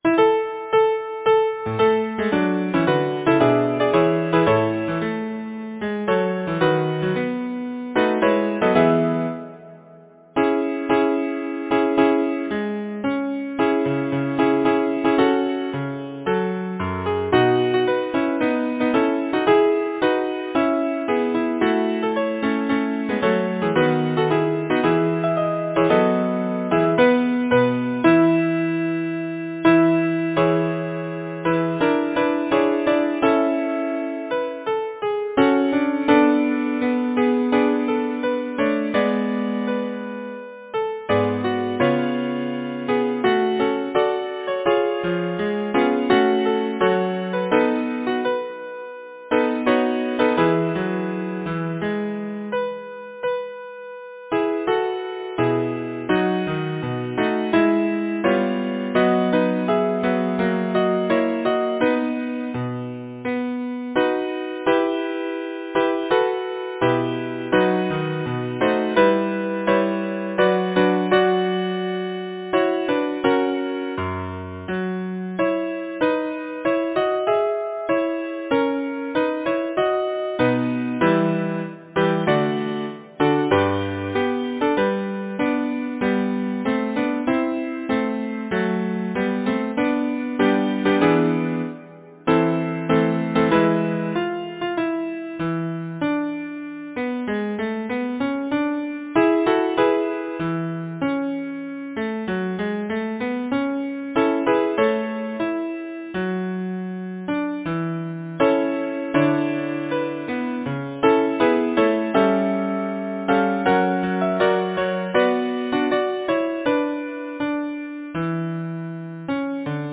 Title: Peace Composer: John Frederick Bridge Lyricist: I. J. Innes Pocock Number of voices: 4vv Voicings: SATB or ATTB Genre: Secular, Partsong, Glee
Language: English Instruments: Piano